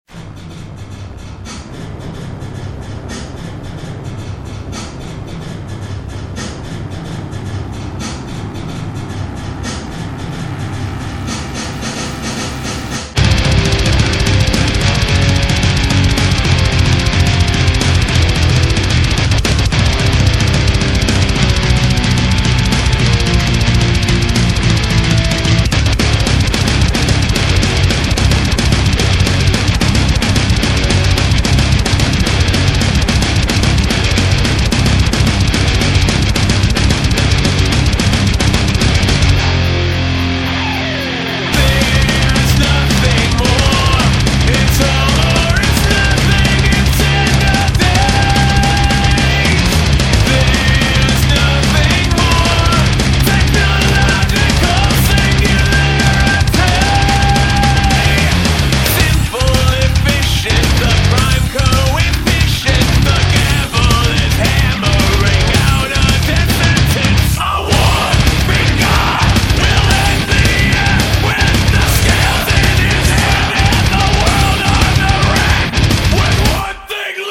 goth rockers